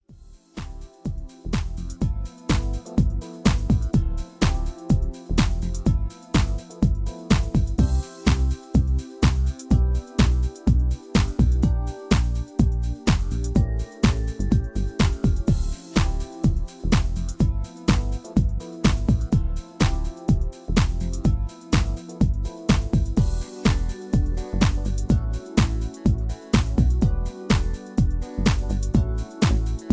Key-C-Eb) Karaoke MP3 Backing Tracks
Just Plain & Simply "GREAT MUSIC" (No Lyrics).